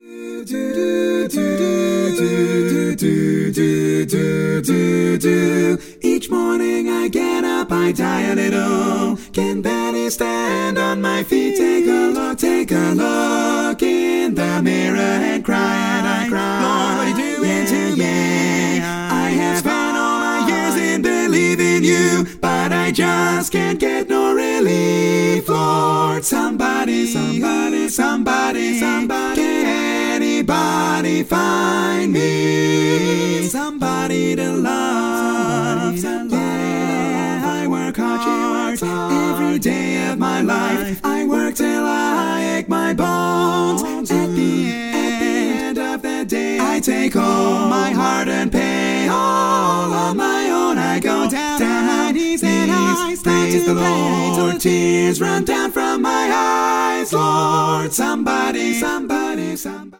Full mix
Category: Female